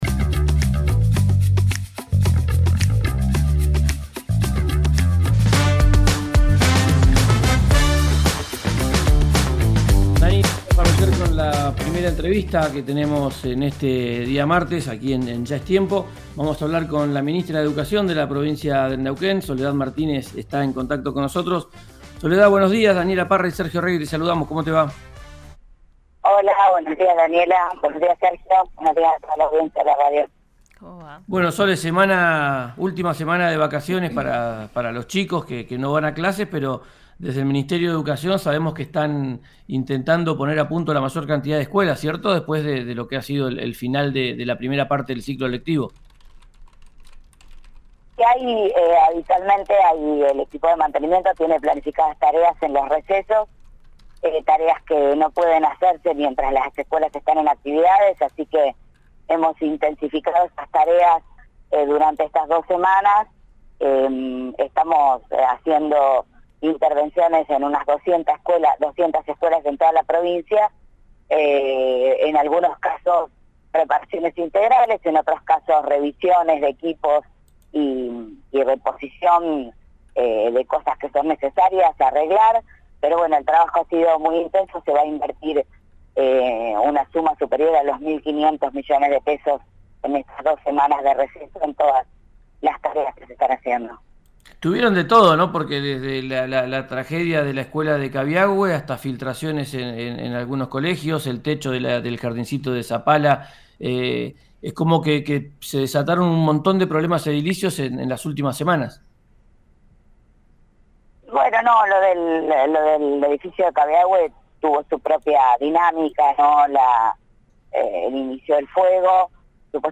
Escuchá a Soledad Martínez, ministra de Educación de Neuquén, por RÍO NEGRO RADIO: